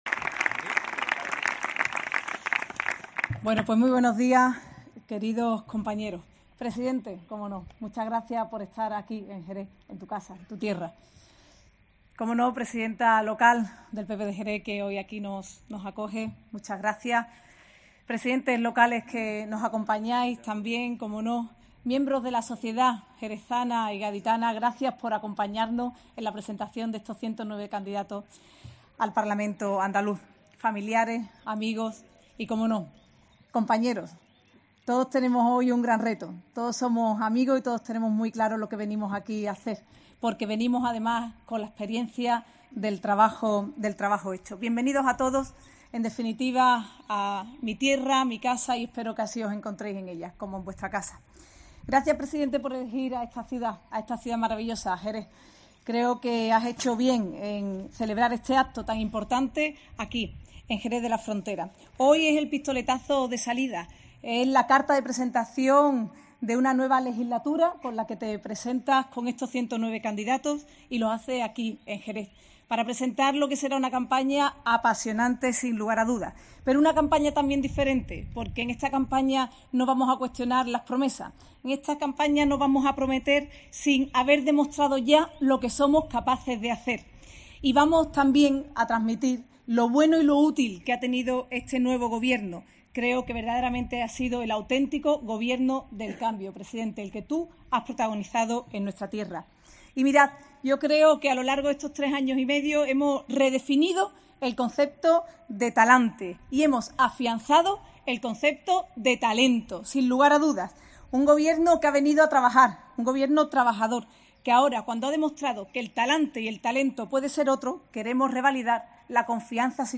Ana Mestre en la presentación de los Candidatos del PP a las elecciones del 19J